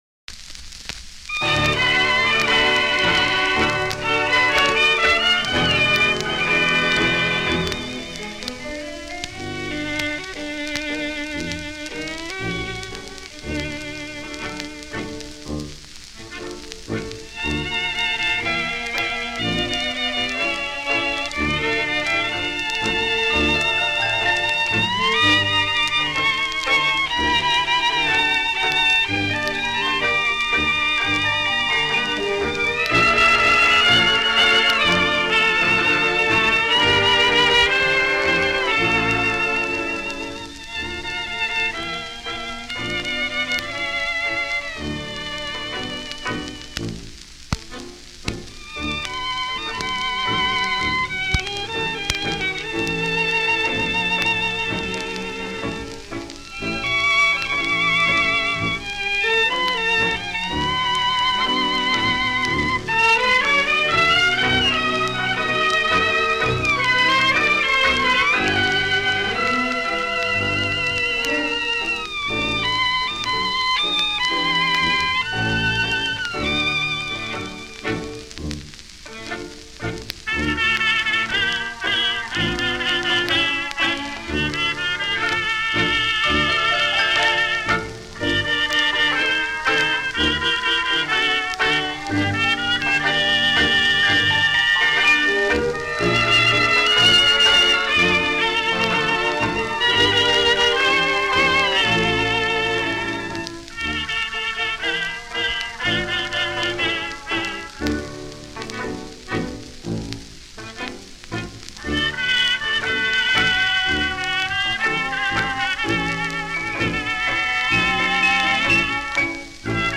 Оркестр
italienischer Tango